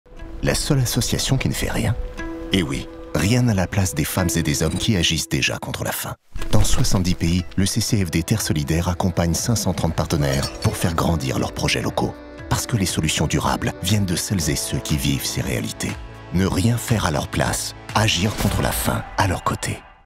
Sérieux pour grandes causes.
Campagne publicitaire pour l’association caritative CCFD – Terre Solidaire.
Enregistré et mixé chez VOA Studios.